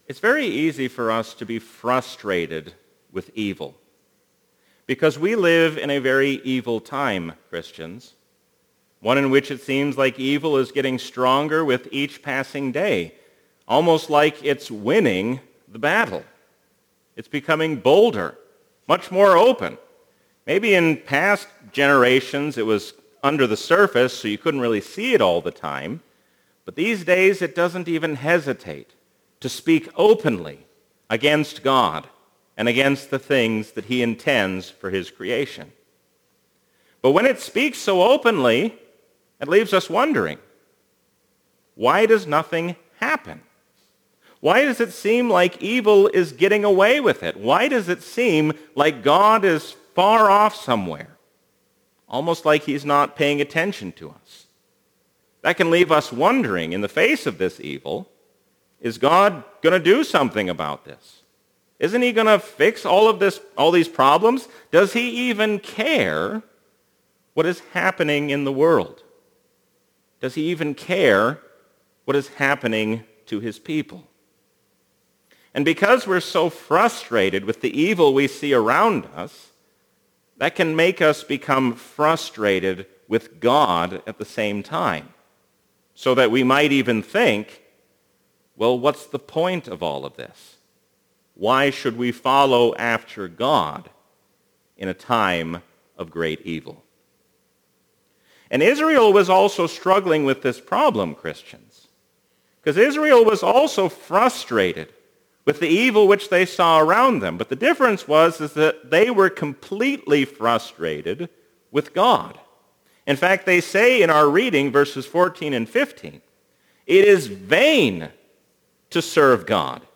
A sermon from the season "Trinity 2021." When frustrated with evil, turn to God who will do something about that evil.